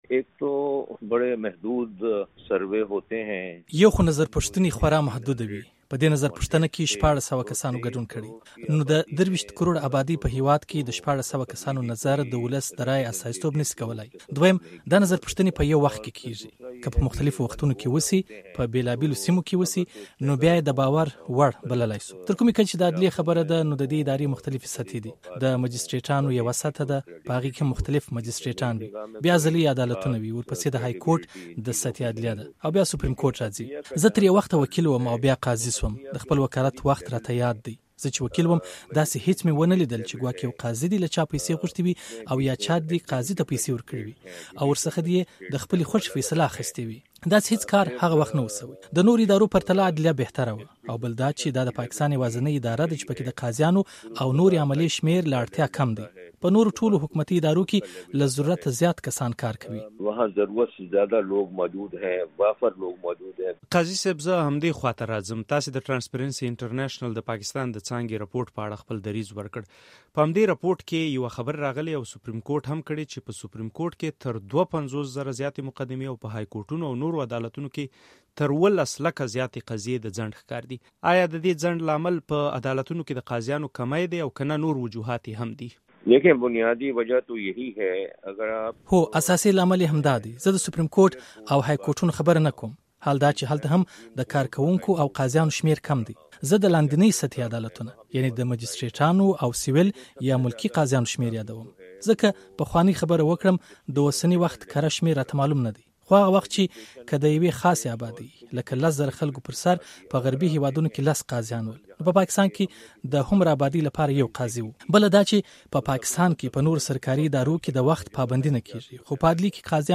په عدلیه کې د درغلۍ تصور په اړه له قاضي وجیه الدین سره مرکه